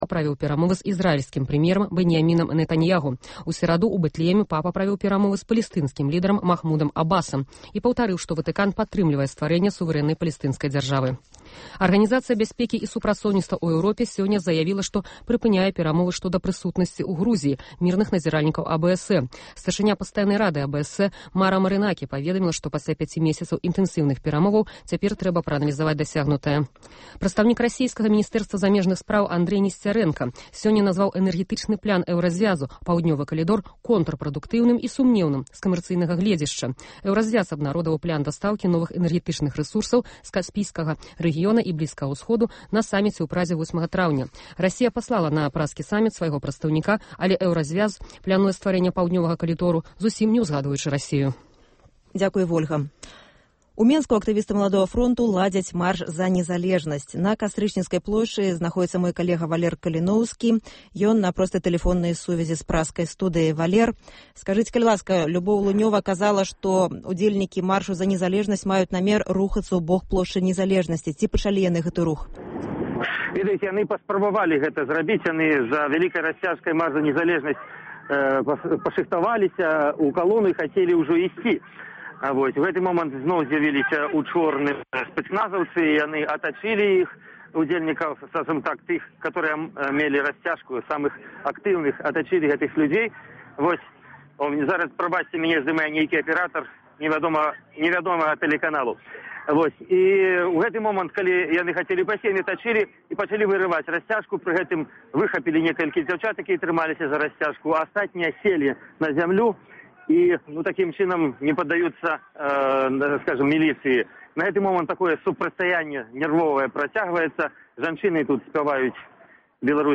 Рэпартаж з маршу "За Незалежнасьць!"